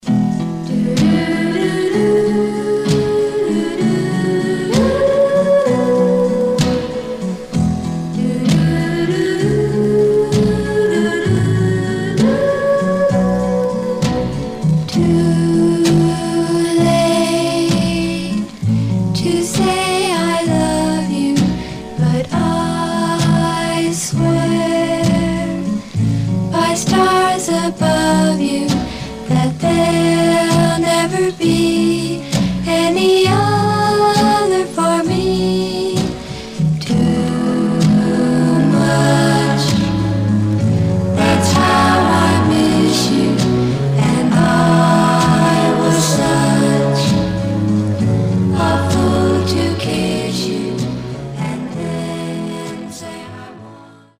Mono
White Teen Girl Groups